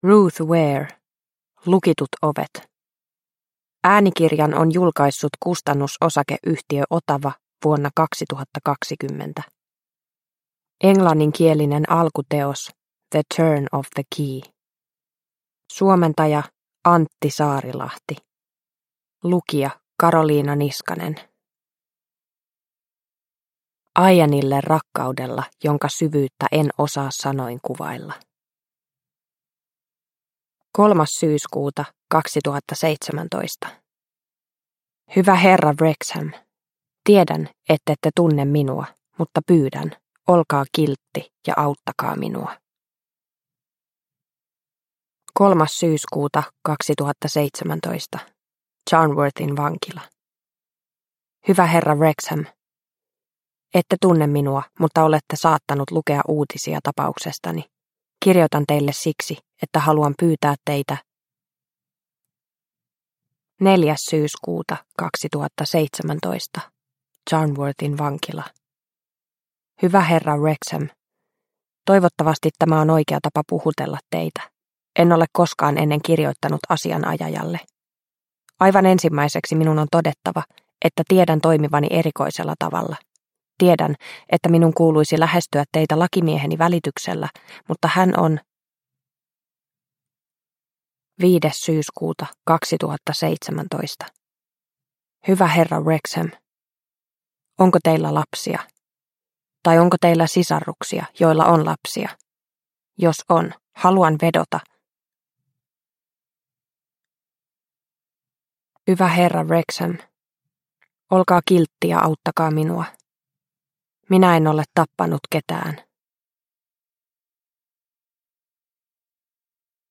Lukitut ovet – Ljudbok – Laddas ner